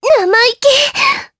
Erze-Vox_Damage_jp_03.wav